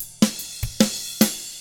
146ROCK F2-L.wav